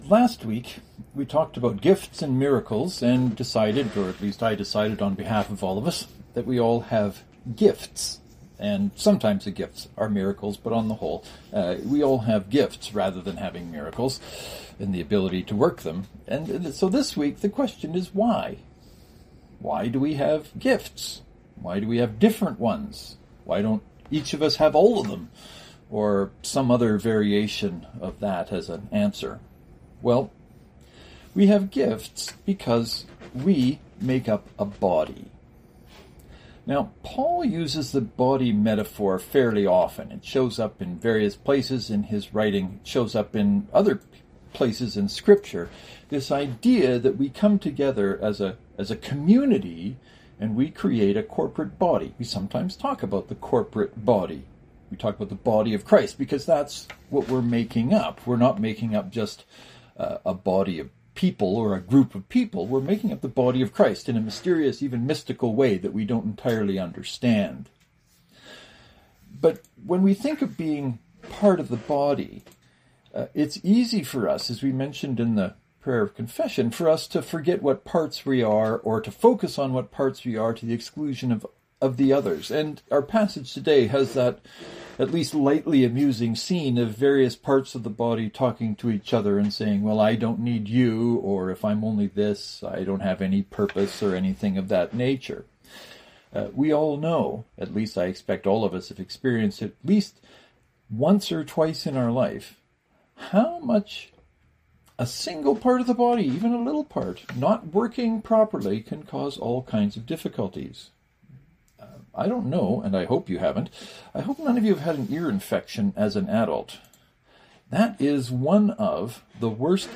I am curious, as virtually always, to hear what you are thinking about the ideas in the sermon.